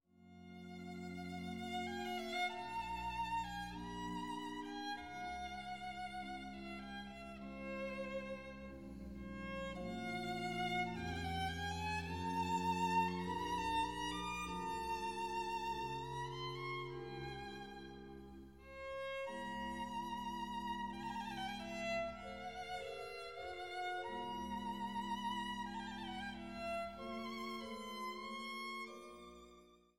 für Violine und Orgel